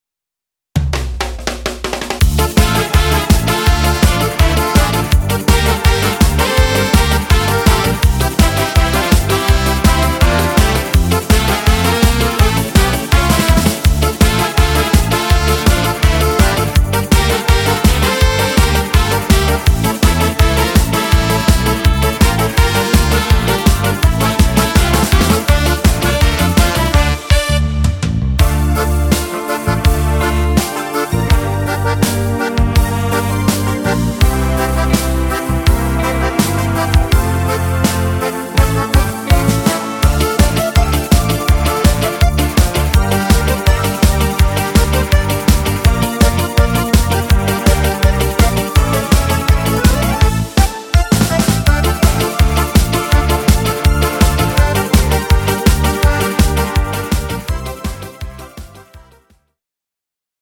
Bardzo taneczny utwór
podkład dla wokalistów
Disco Polo